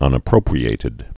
(ŭnə-prōprē-ātĭd)